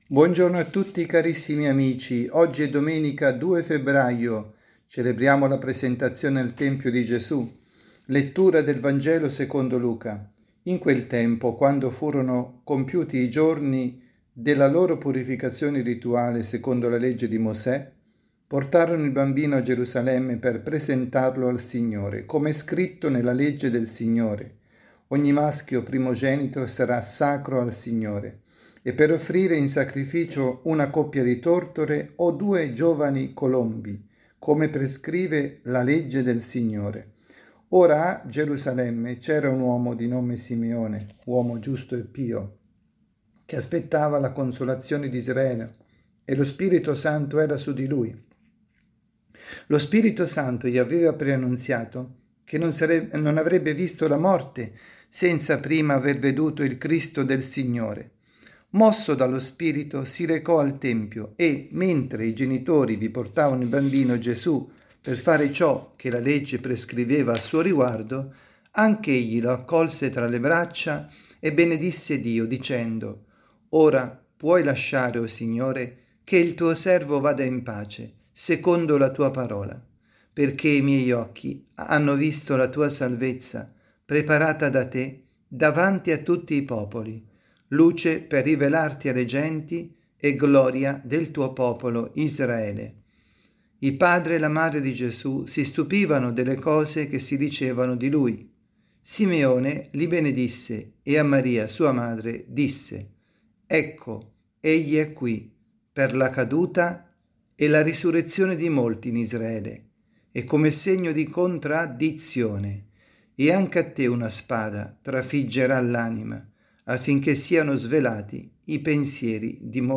avvisi, Omelie